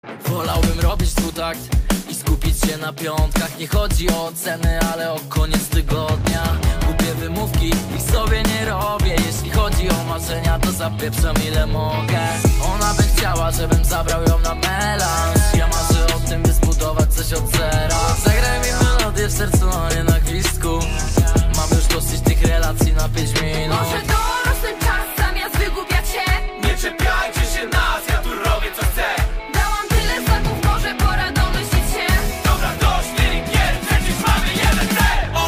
Kategorie Remixy